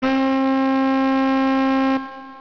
TENORSAX.WAV